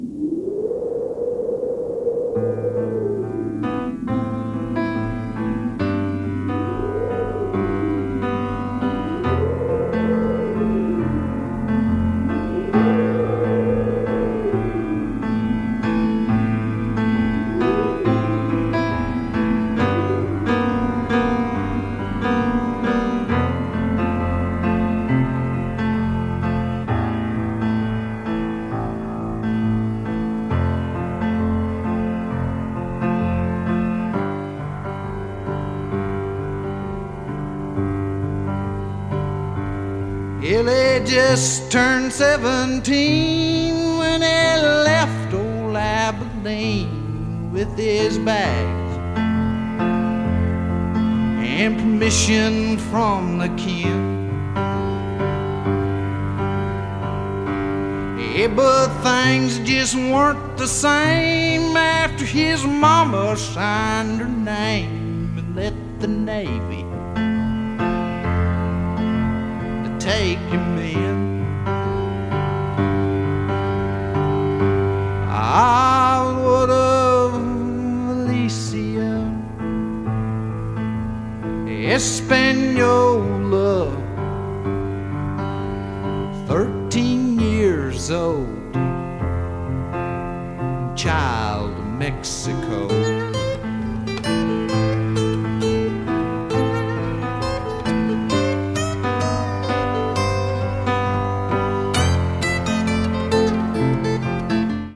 コンセプト・アート・カントリーの大傑作！不穏な空気が渦巻くある種の過激さを美しい歌唱で表現！